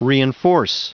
Prononciation du mot reinforce en anglais (fichier audio)
Prononciation du mot : reinforce